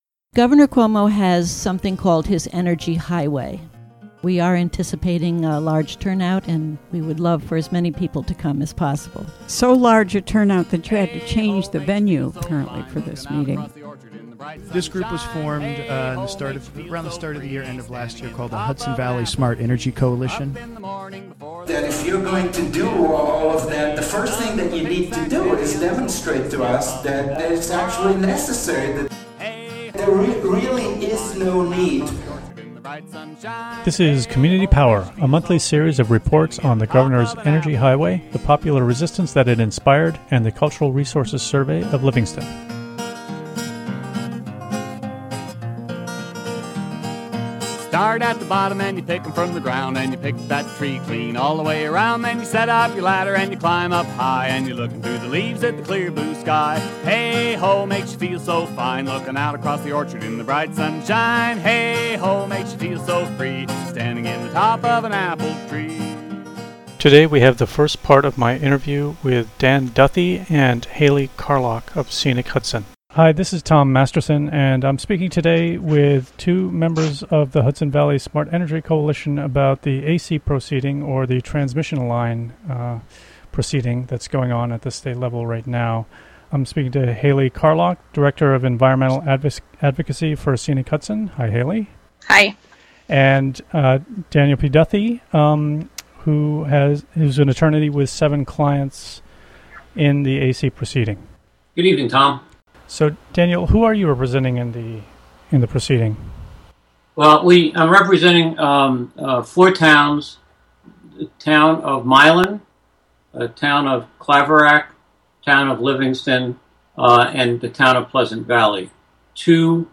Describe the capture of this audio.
Recorded in the WGXC Hudson Studio, Wed., Jul. 29.